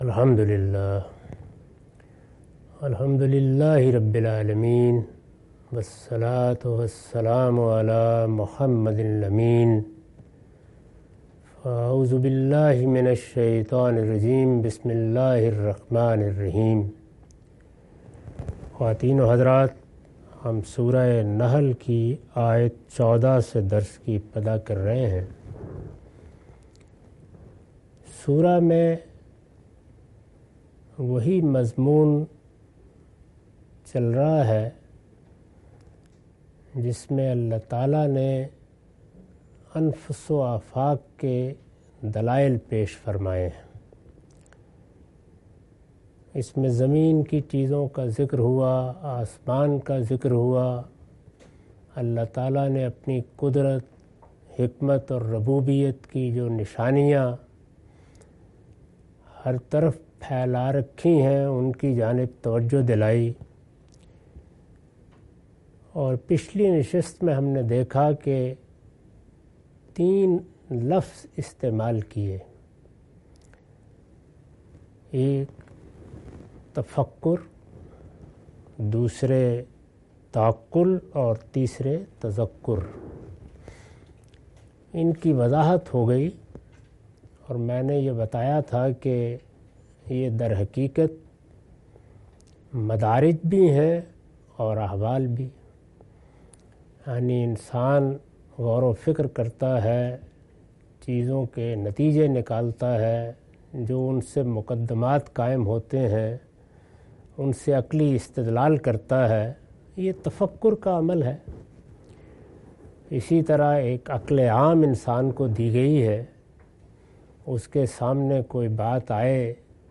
Surah Al-Nahl- A lecture of Tafseer-ul-Quran – Al-Bayan by Javed Ahmad Ghamidi. Commentary and explanation of verses 14-21.